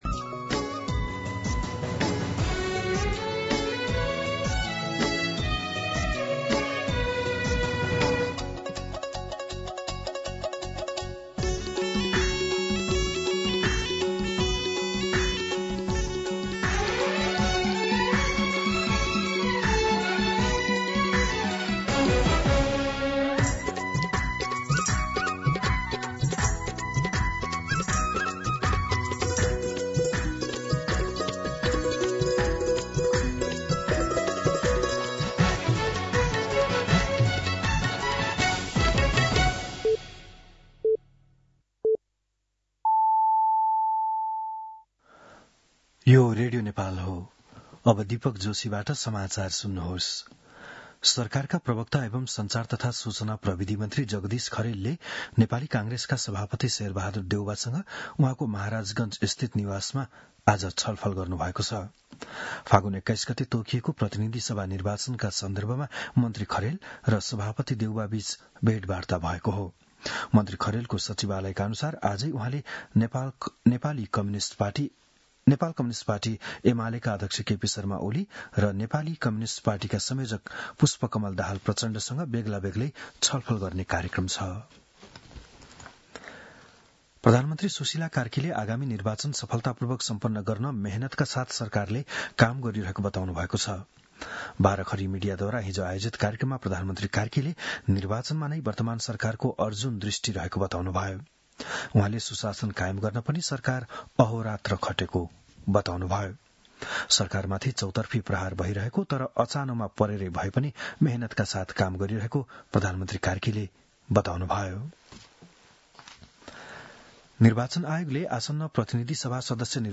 बिहान ११ बजेको नेपाली समाचार : ११ पुष , २०८२